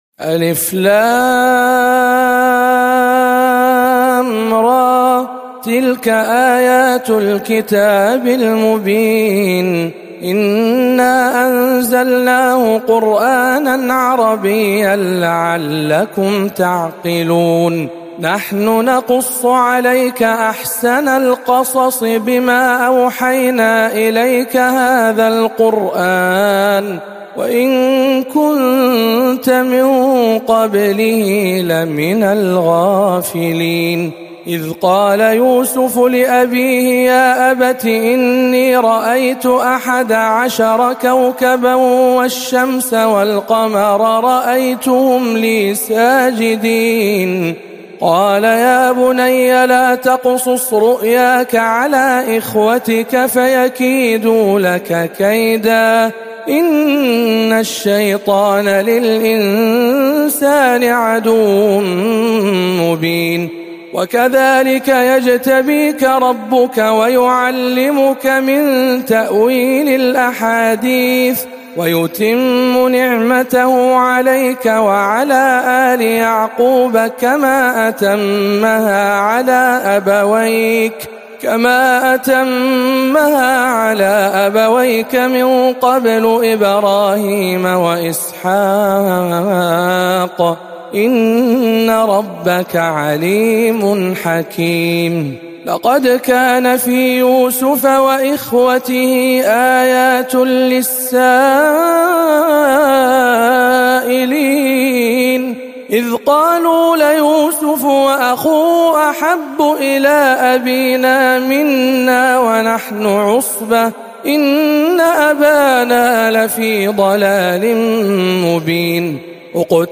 سورة يوسف بجامع أم الخير بجدة